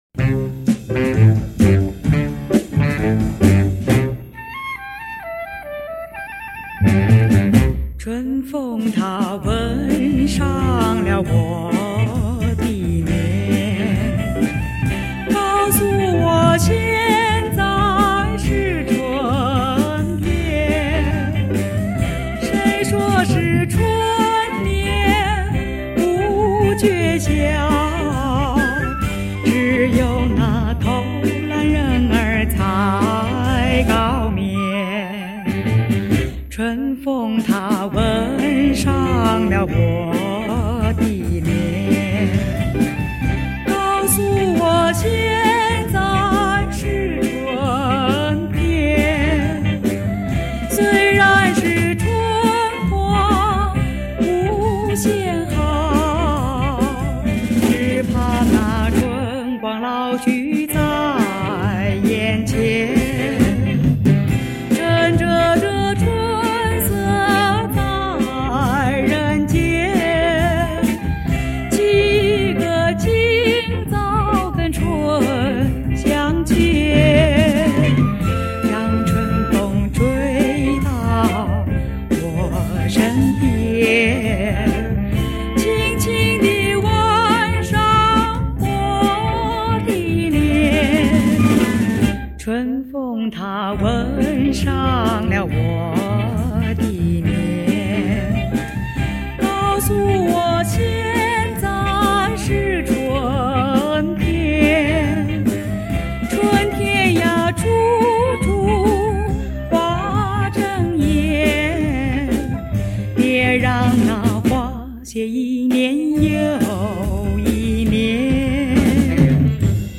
60年代的原音原唱
2000年的电脑数位处理